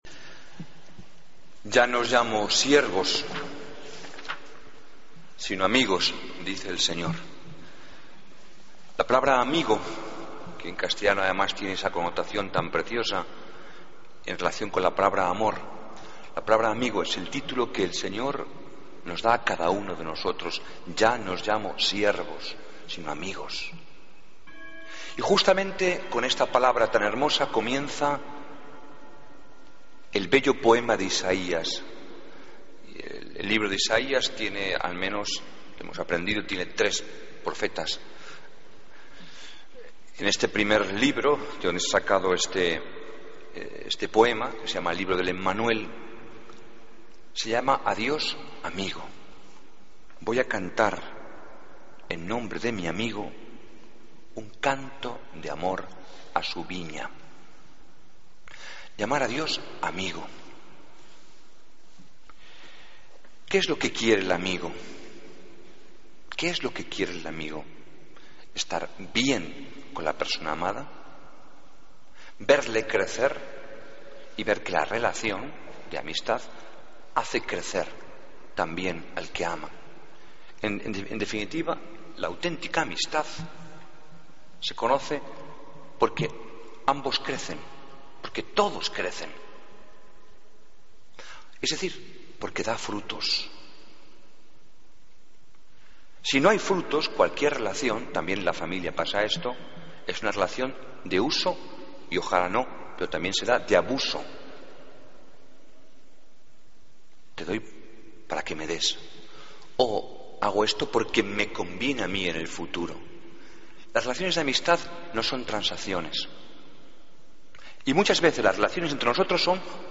Homilía del Domingo 5 de Octubre de 2015